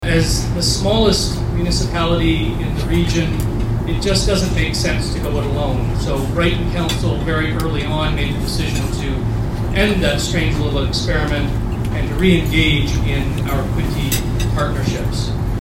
At Belleville’s Ramada Hotel, they welcomed the Municipality of Brighton to the event, which focused on thanking the business community, and talked about the changing workplace.